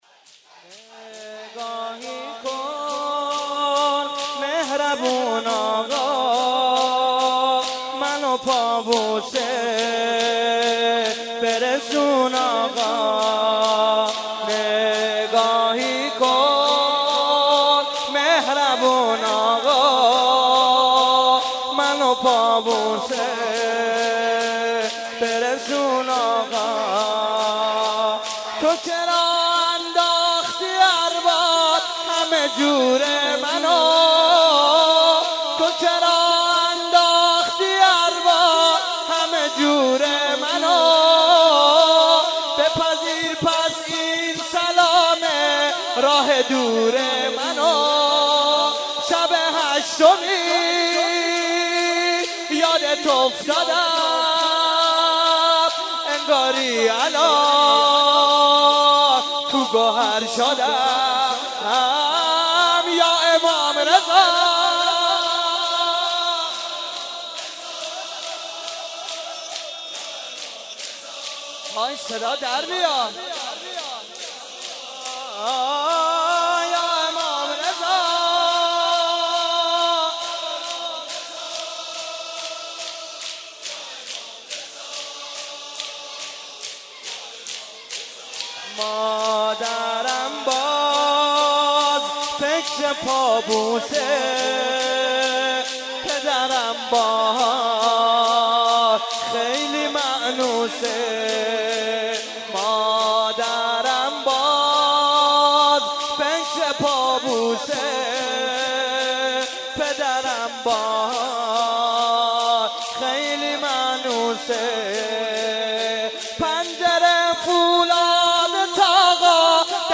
شب سوم رمضان 95، حاح محمدرضا طاهری
04.sineh zani2.mp3